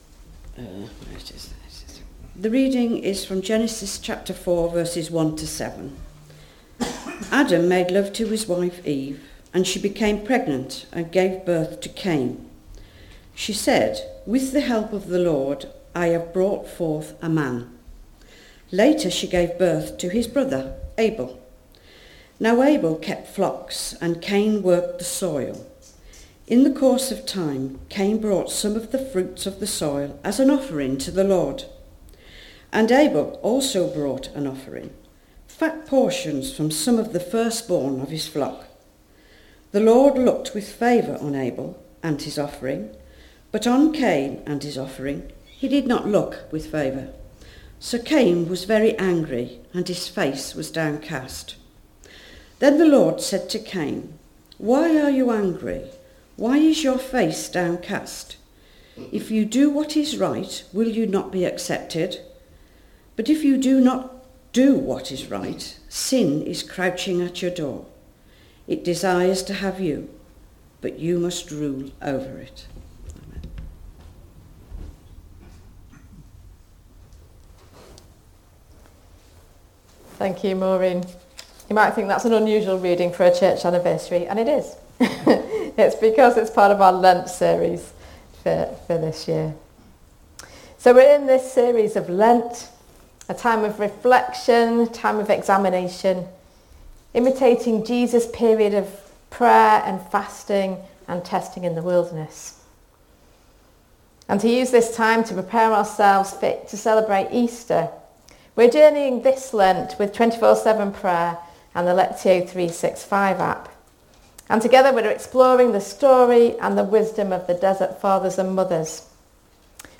You can listen to the reading and sermon by tapping the link below: